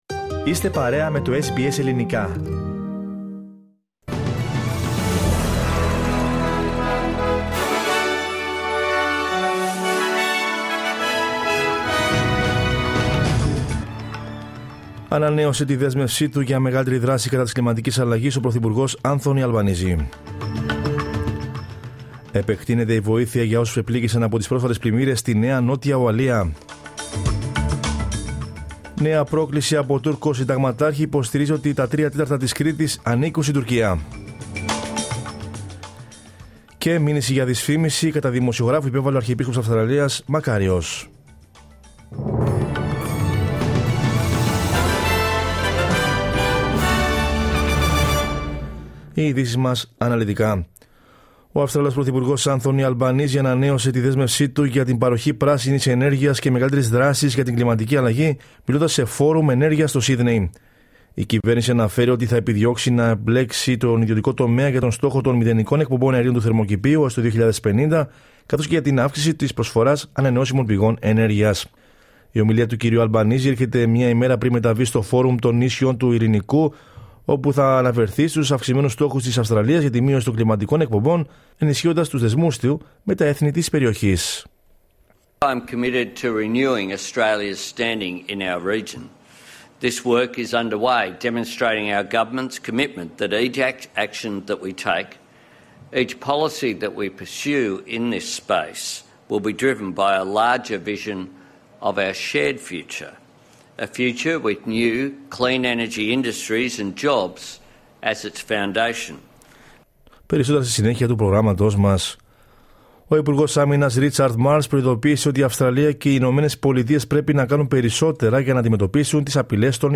News in Greek from Australia, Greece, Cyprus and the world is the news bulletin of Tuesday 12 July 2022.